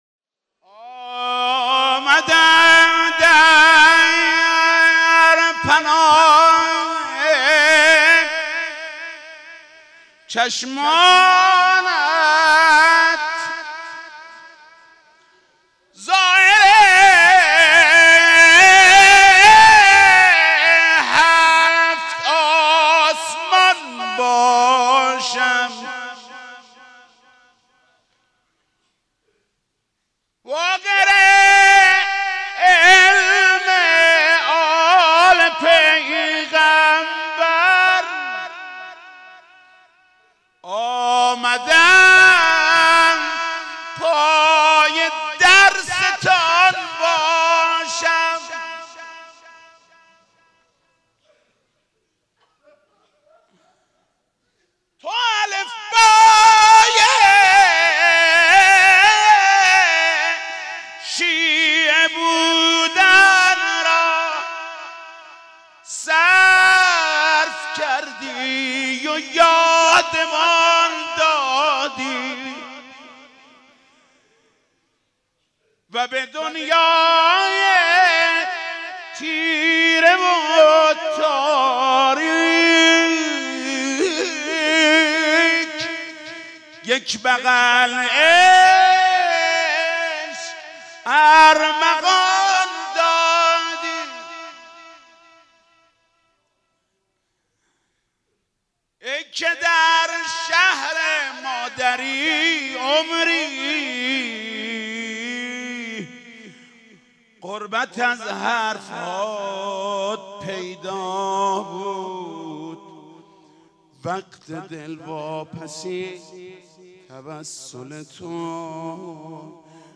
شهادت حضرت امام محمد باقر علیه السلام 1393 | مسجد حضرت امیر | حاج محمود کریمی
آمدم در پناه چشمانت | روضه | حضرت امام محمد باقر علیه السلام